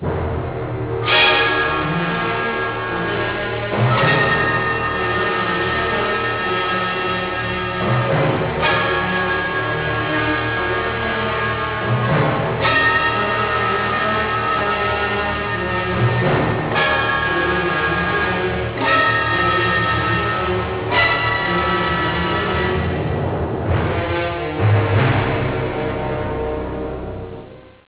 una folta e possente orchestra